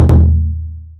～【効果音】～
どどん(低音)